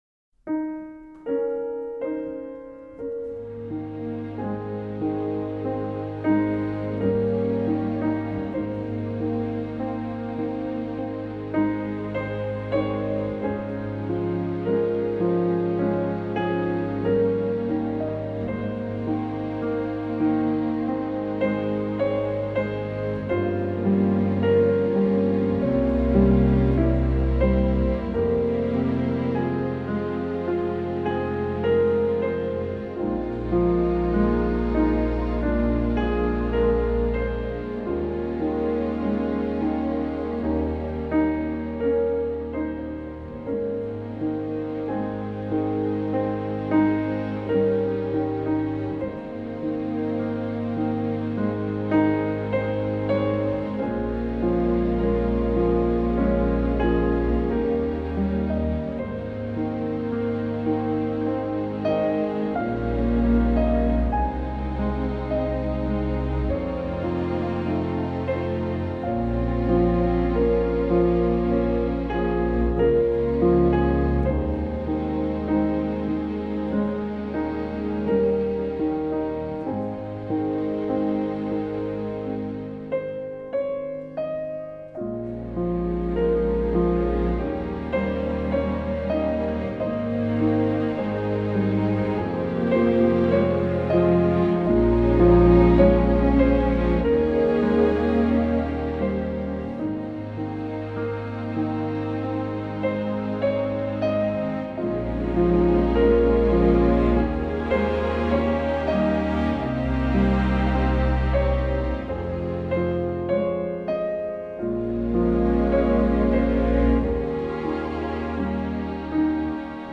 부드럽고 다정한 느낌 - Douce tendresse-....mp3